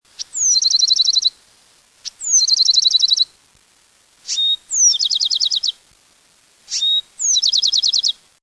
Thryomanes bewickii (Bewick's wren)
Recorded at Tavasci Marsh [Arizona]. It's composed of two "doublets".